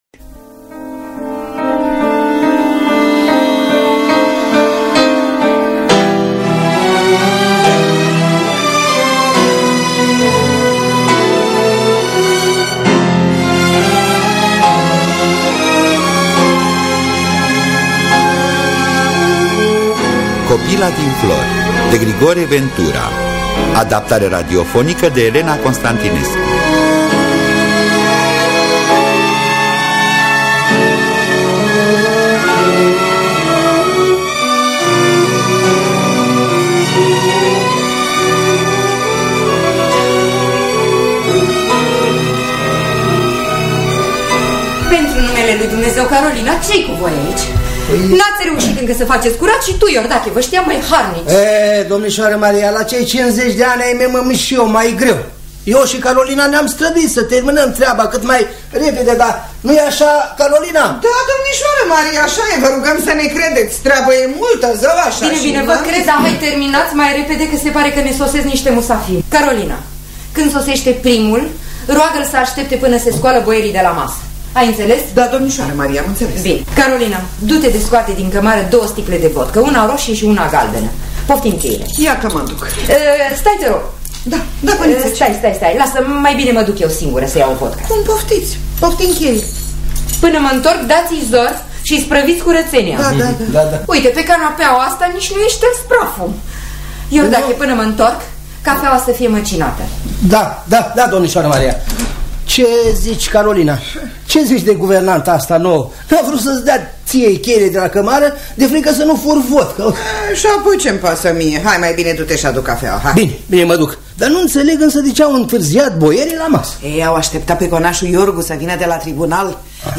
Copila din flori de Grigore Ventura – Teatru Radiofonic Online